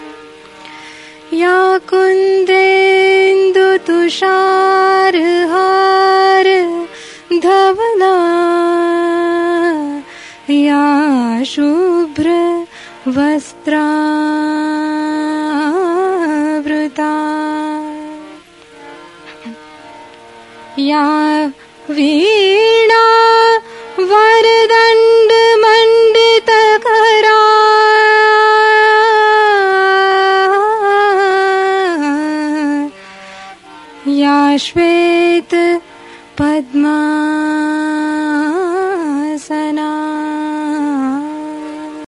live excerpt / 1974